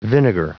Prononciation du mot vinegar en anglais (fichier audio)
Prononciation du mot : vinegar